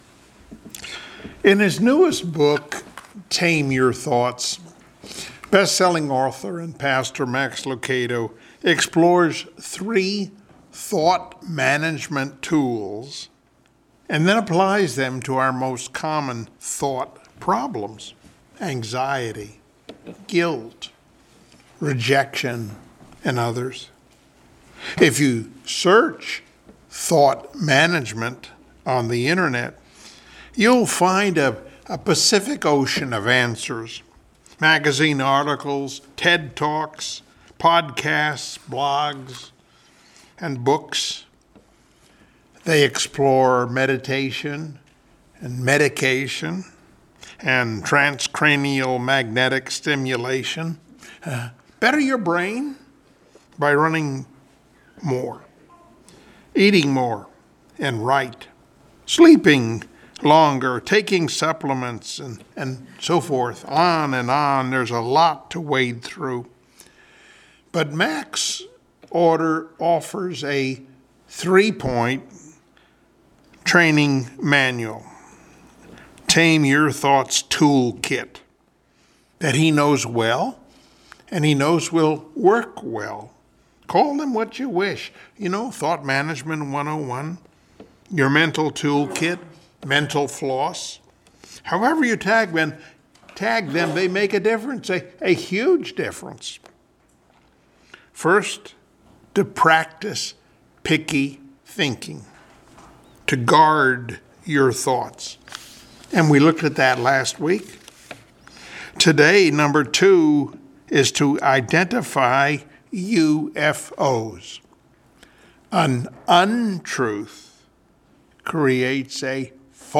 Passage: Mark 12:30 Service Type: Sunday Morning Worship